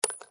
GrenadeDrop.wav